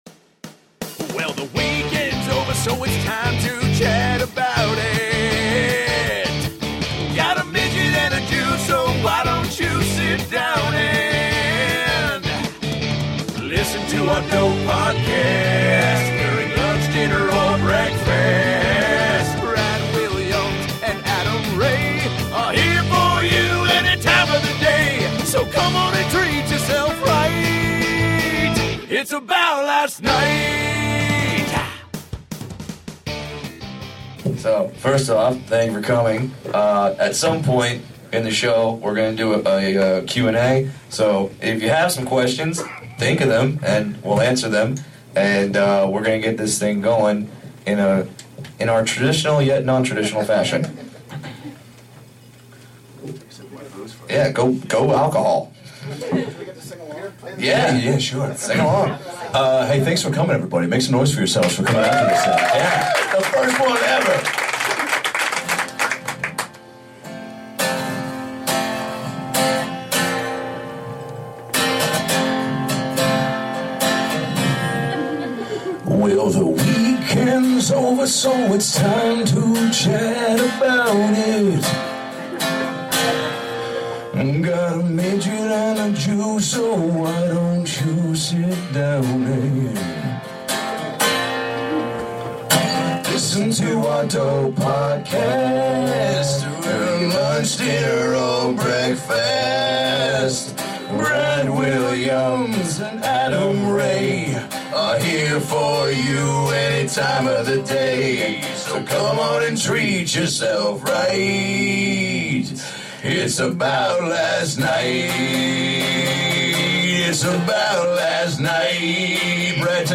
ABL - Live Episode!